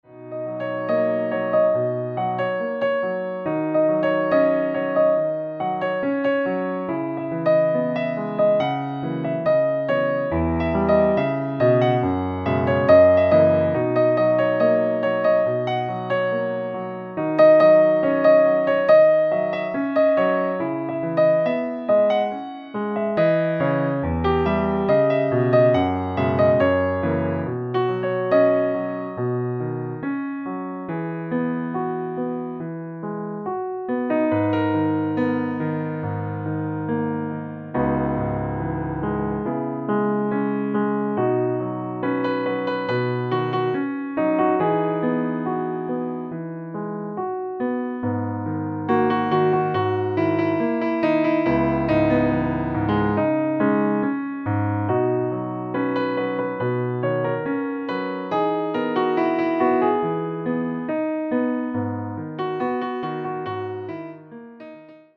Partitura Piano (acompañamiento)
Tono original: B